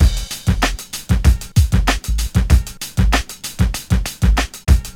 • 97 Bpm Rap Drum Loop Sample D Key.wav
Free drum loop sample - kick tuned to the D note. Loudest frequency: 1084Hz
97-bpm-rap-drum-loop-sample-d-key-FLF.wav